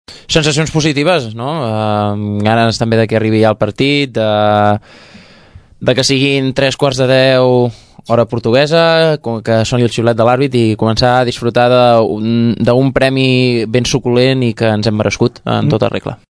En declaracions a Ràdio Tordera ens explica quines sensacions tenen per afrontar aquesta competició.